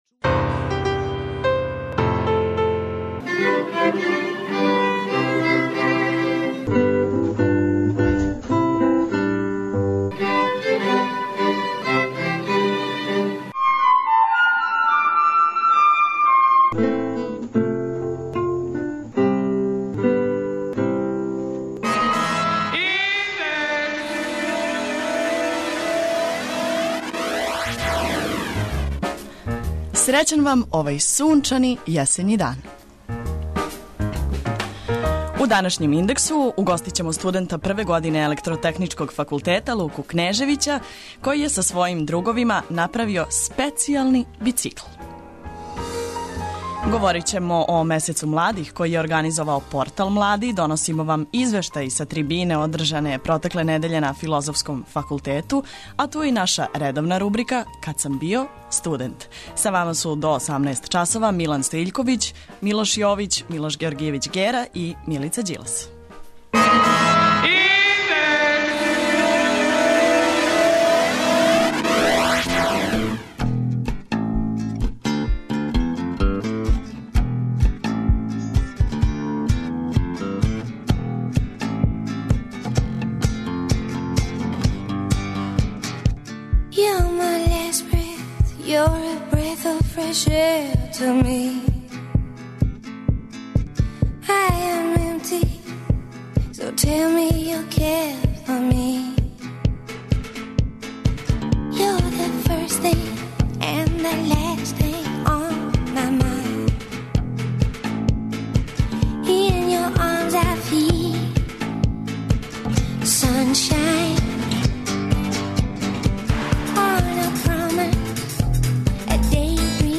Доносимо вам извештај са трибине која је одржана ове недеље на Филозофском факултету, а ту су и редовне студентске вести и рубрика "Кад сам био студент".